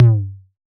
RDM_TapeA_SY1-Tom02.wav